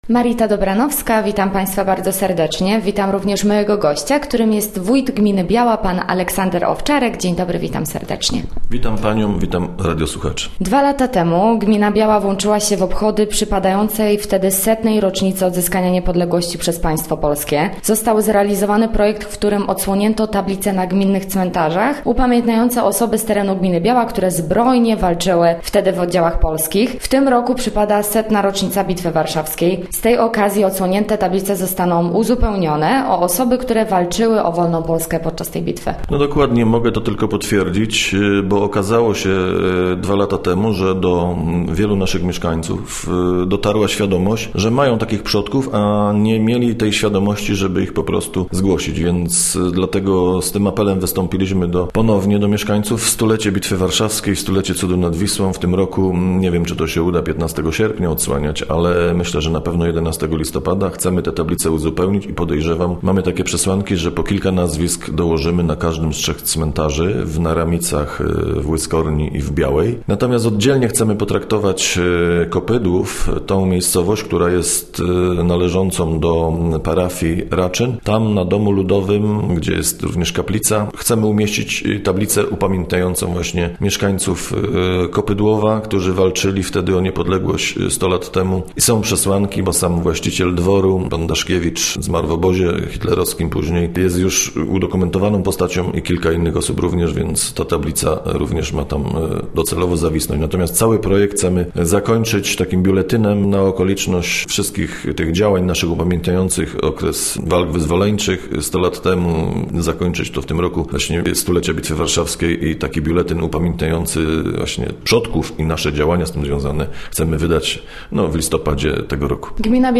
Gościem Radia ZW był Aleksander Owczarek, wójt gminy Biała